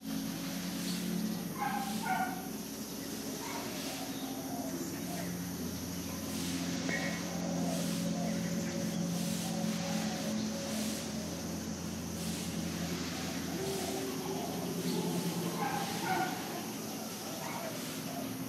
Temple Ambience.wav